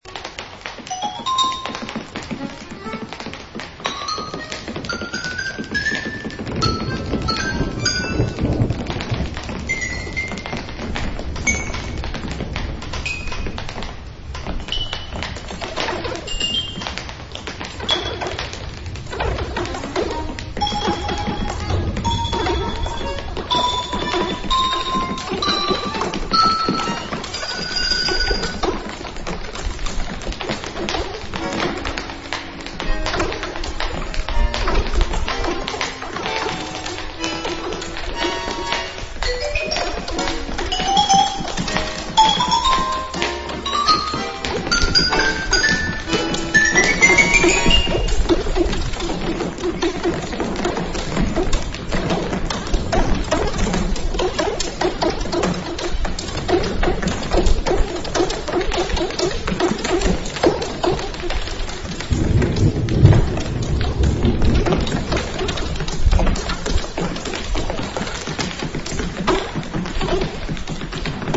compilation of 30 Austrian composers on 4 CDs